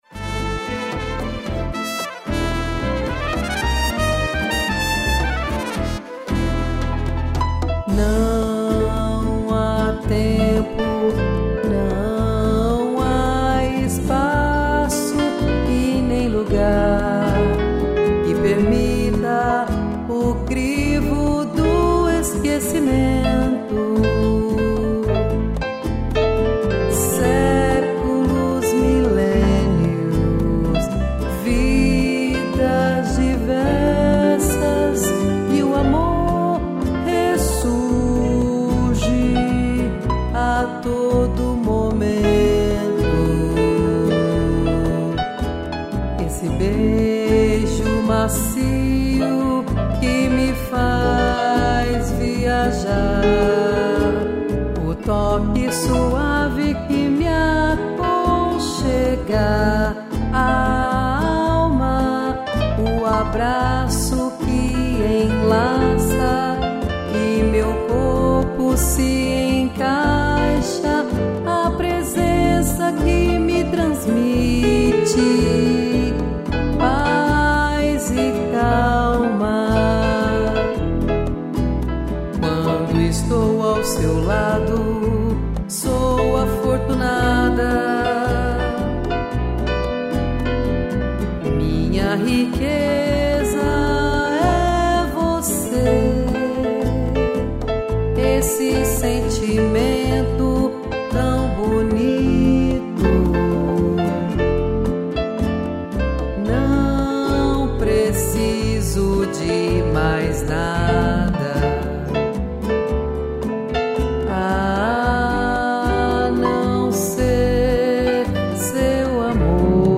2 pianos